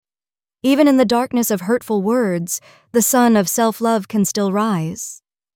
🎤 Emotional Quote TTS